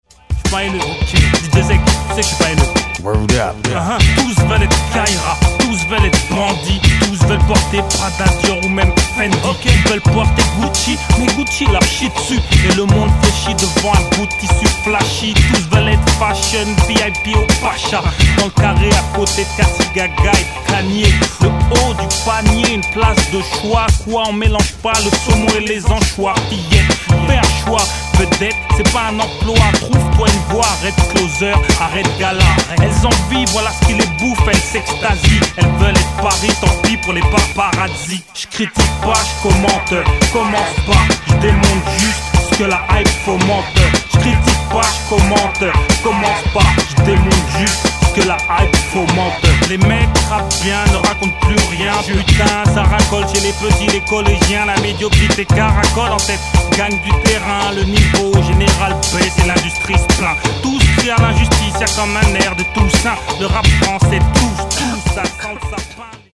Catégorie : Rap